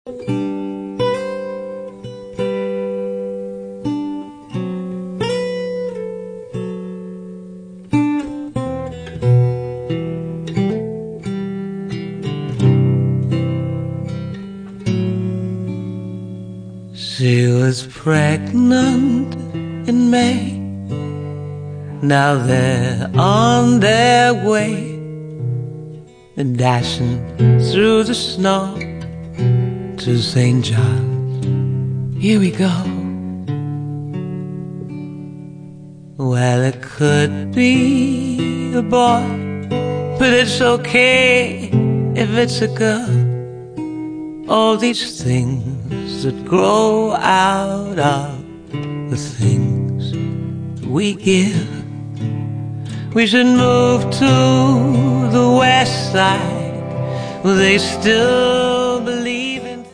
voce
chitarre